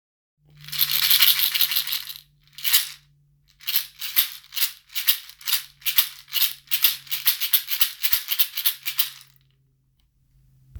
竹ボックスシェイカー ハンドル付
カラカラジャカジャカ♪乾いたアフリカンサウンドをどうぞ。
素材： 竹 木 小石
仕入国： カメルーン